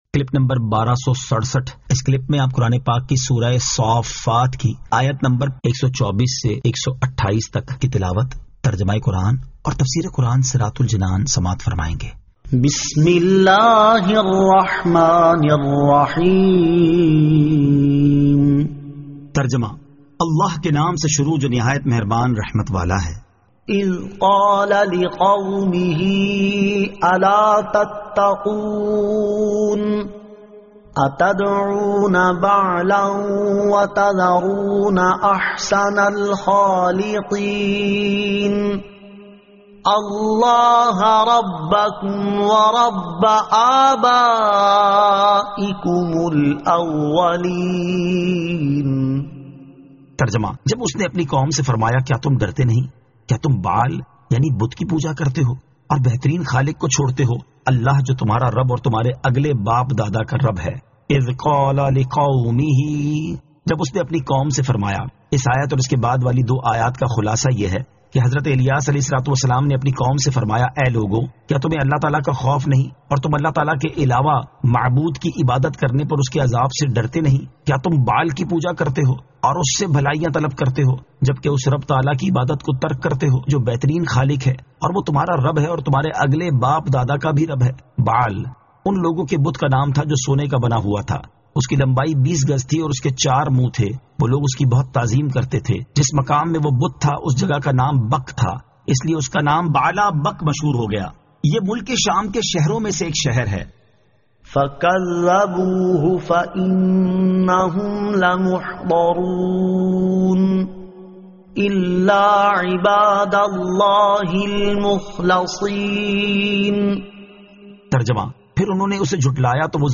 Surah As-Saaffat 124 To 128 Tilawat , Tarjama , Tafseer